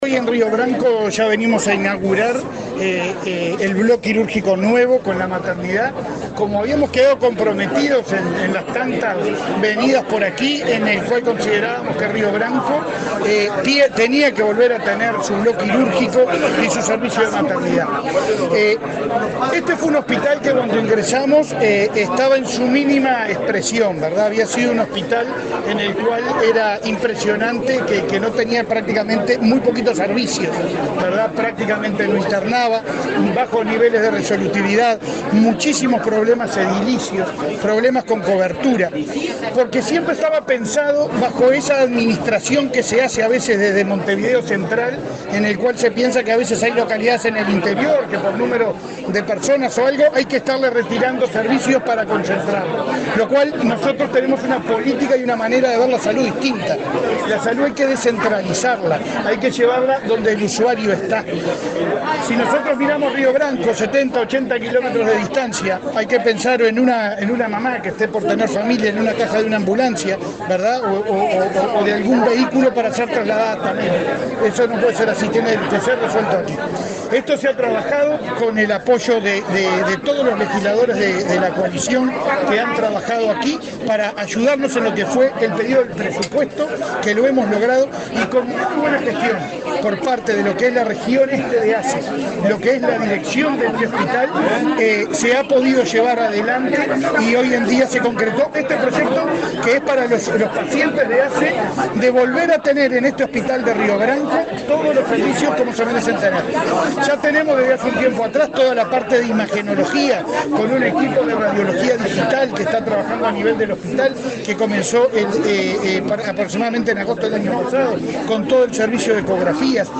Declaraciones del presidente de ASSE, Leonardo Cipriani
Este lunes 6, el presidente de la Administración de los Servicios de Salud del Estado (ASSE), Leonardo Cipriani, dialogó con la prensa en Cerro Largo,